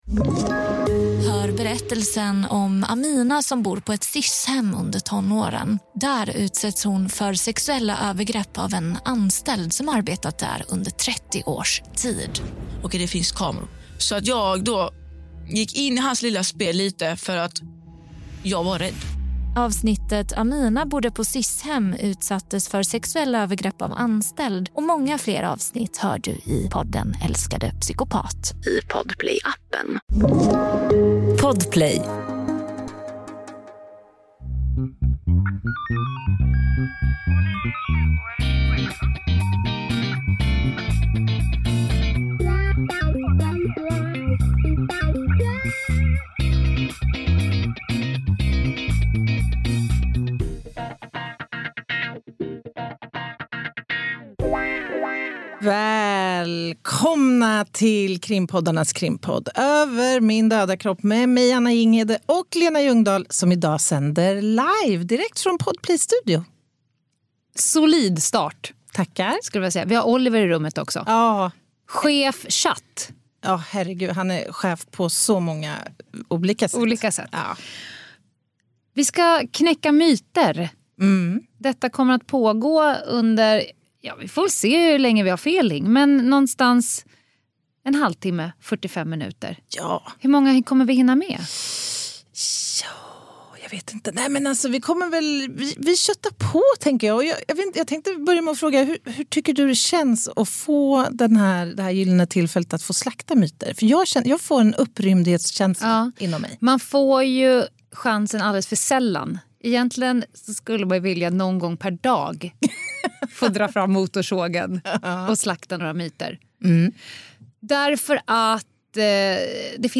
280. Livepodd: Myter och motorsåg